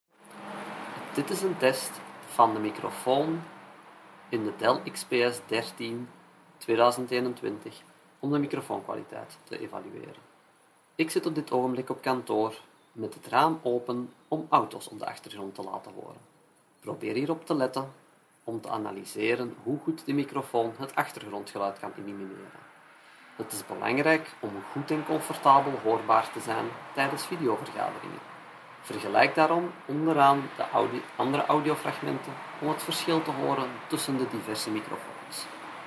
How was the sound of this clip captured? As tastes differ, we have provided below a series of sound clips in which we test the microphone of a webcam, laptop, speakerphone, headset and earphone, among others. Dell XPS 13 (2021) (Laptop Microphone):